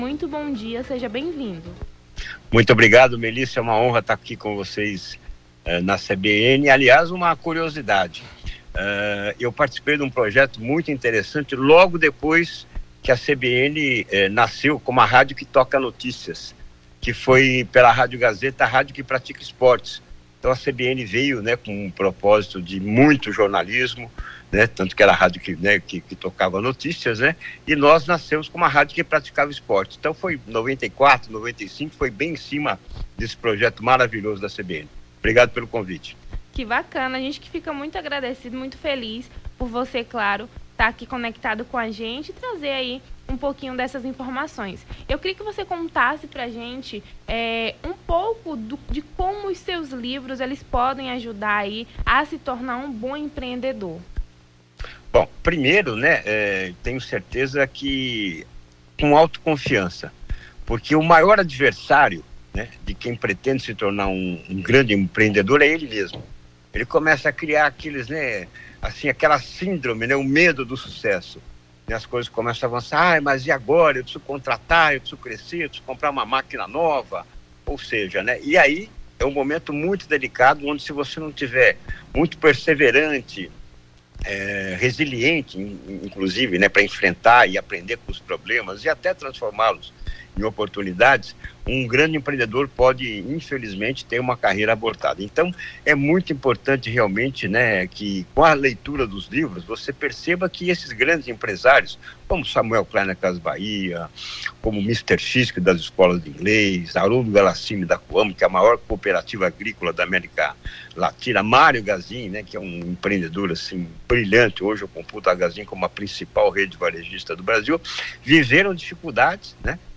CBN entrevista o biógrafo dos maiores empreendedores do Brasil